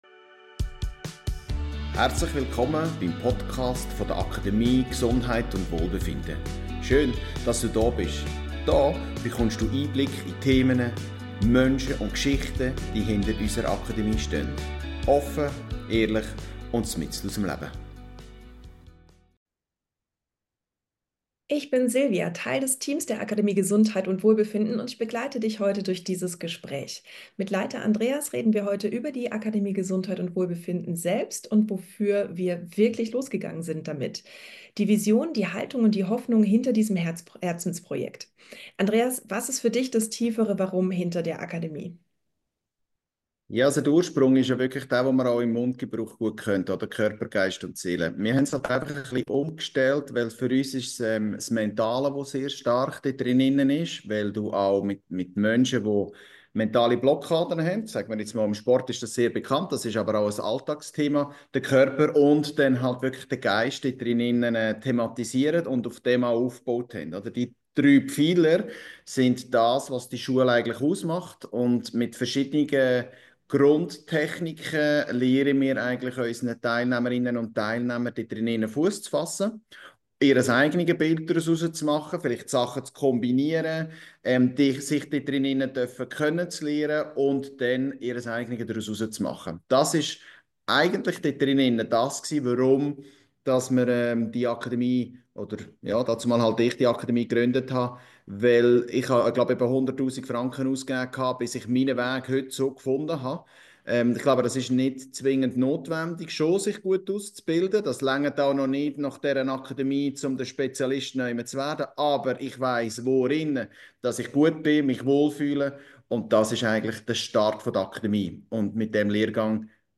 Ein Gespräch über Praxisnähe, Ganzheitlichkeit, Mut und Menschlichkeit.